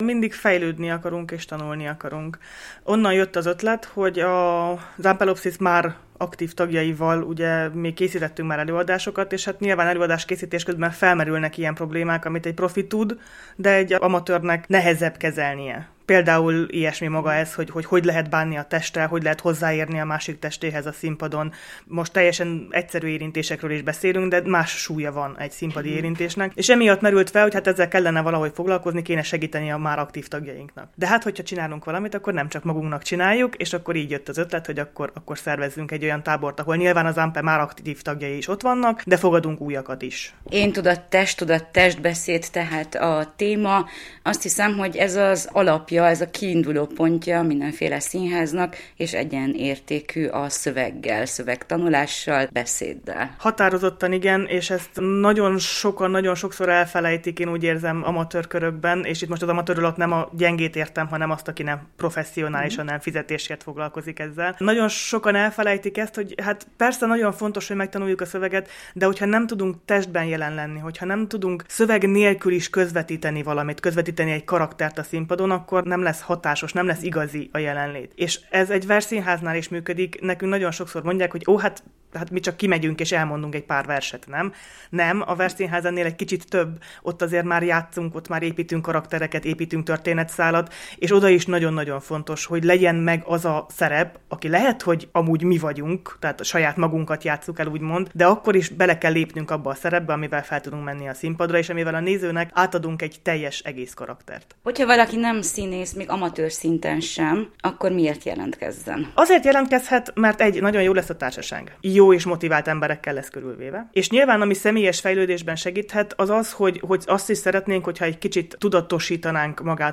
a versszínház egyik kezdeményezője beszélt nekünk a részletekről.